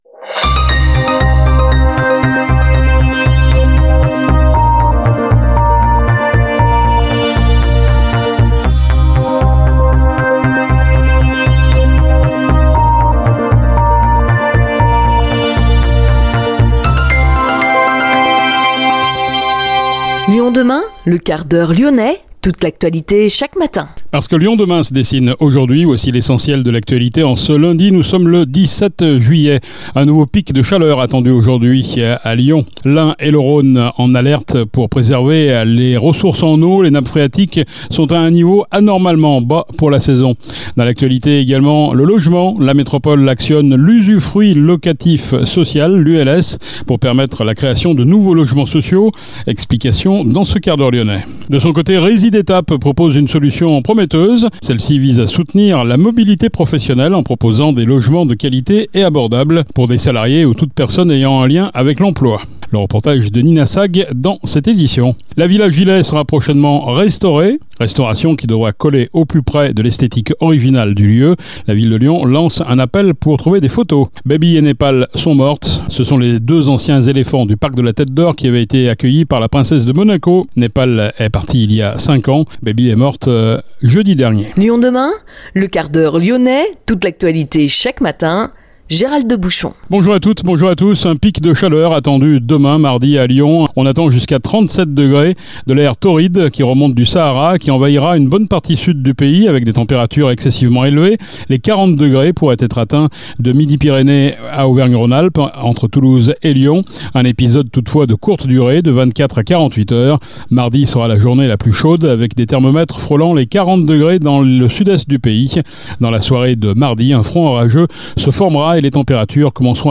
« Dans cette édition, focus sur le modèle Résidétape à Gerland Alors que la crise du logement à Lyon pèse sur les travailleurs et leurs employeurs, Résidétape propose une solution prometteuse.